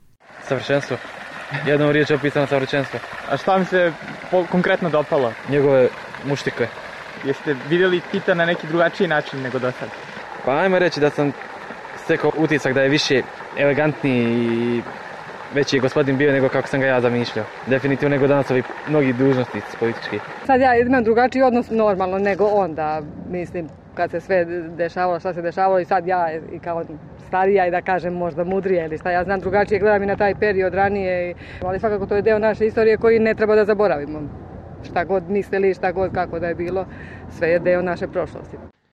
Posetioci o izlozbi